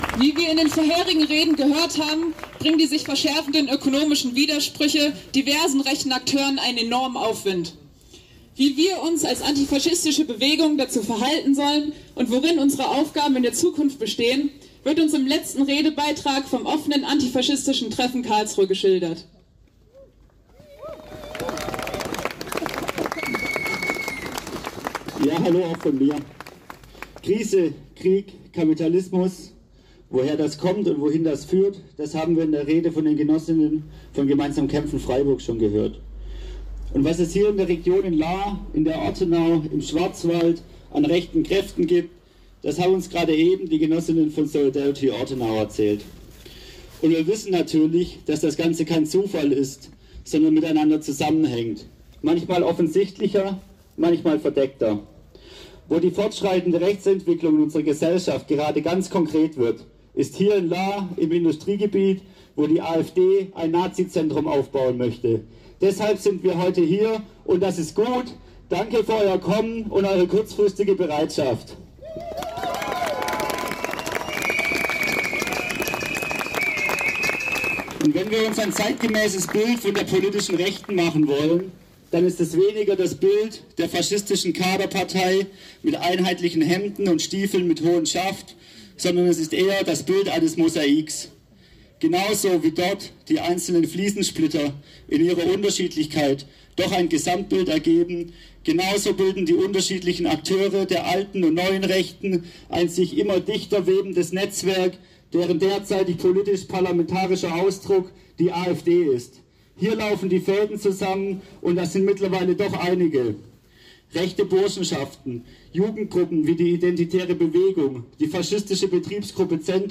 Hier sind einige Eindrücke von Teilnehmenden und die Redebeiträge der Kundgebungen zum Nachhören zu finden:
Redebeitrag des Offenen Antifaschistischen Treffens Karlsruhe